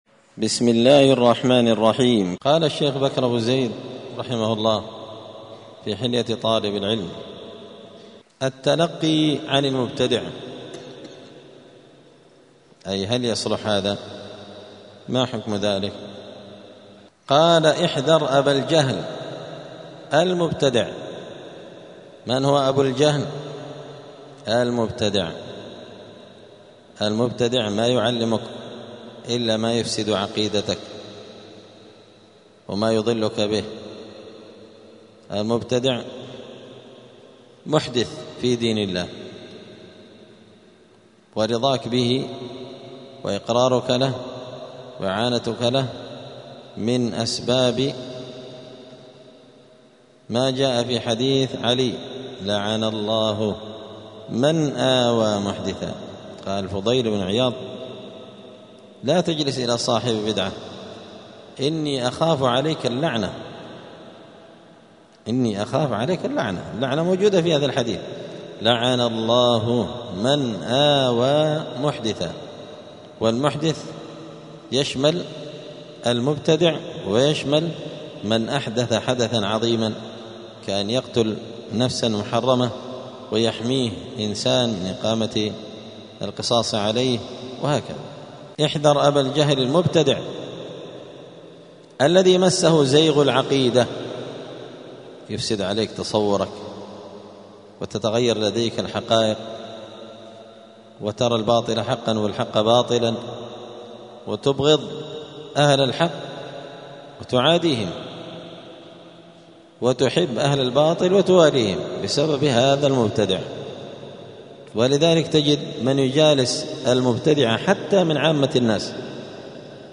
*الدرس السابع والثلاثون (37) {فصل أدب الطالب مع شيخه التلقي عن المبتدع}*
الأربعاء 26 صفر 1447 هــــ | الدروس، حلية طالب العلم، دروس الآداب | شارك بتعليقك | 12 المشاهدات